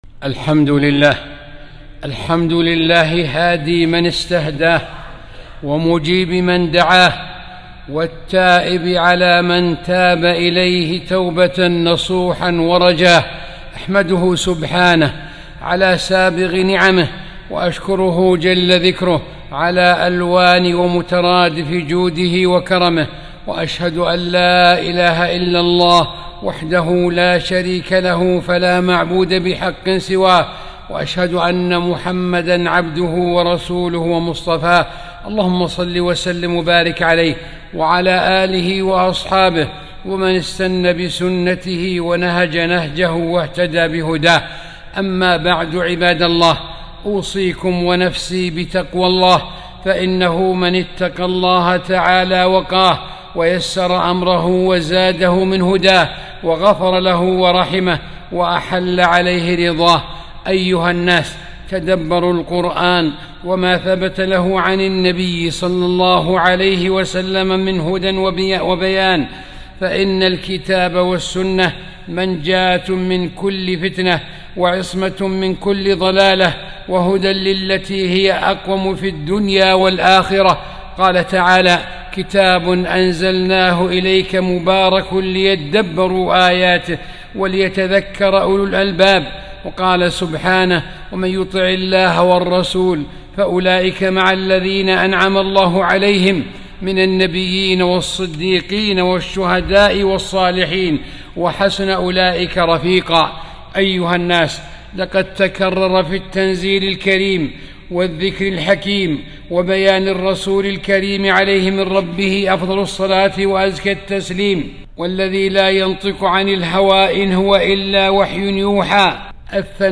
خطبة - قسوة القلوب، مظاهرها، وعلاجها